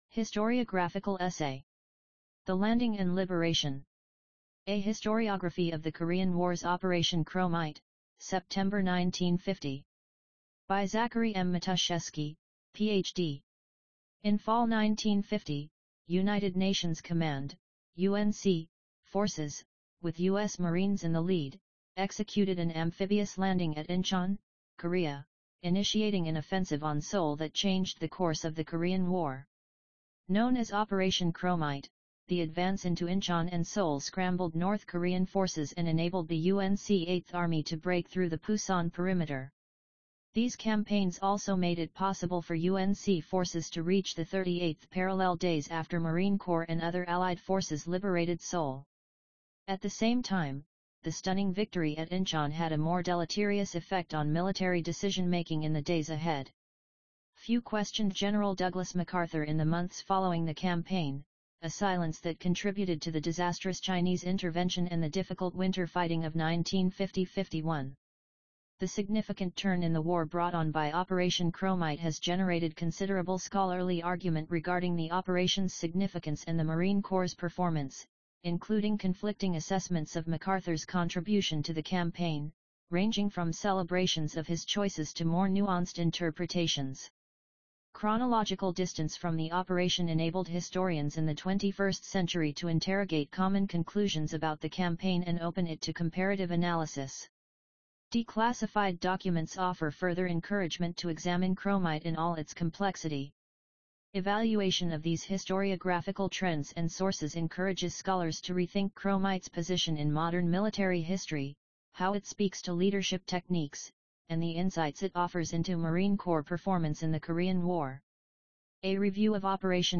MCH_9_2_Winter_Matusheski_AUDIOBOOK.mp3